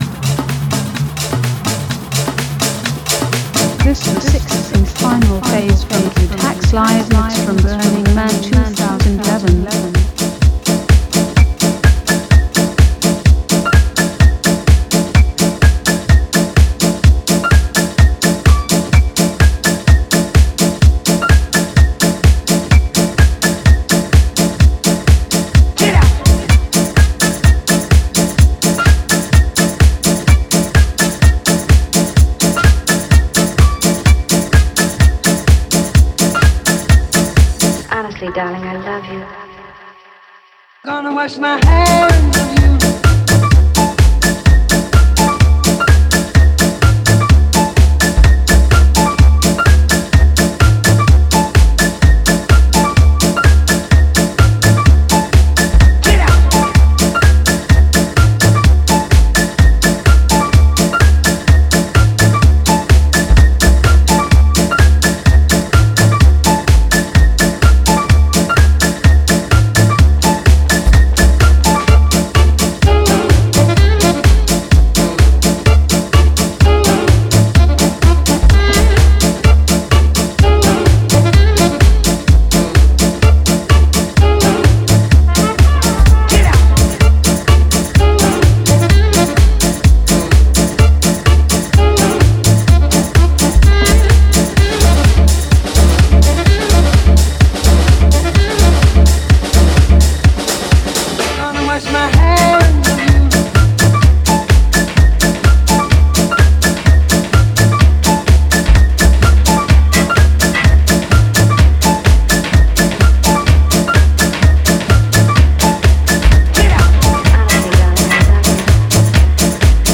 San Francisco DJ spinning house, techno & bass.
Deep House, Organic House
Mendocino Magic, CA - September, 2021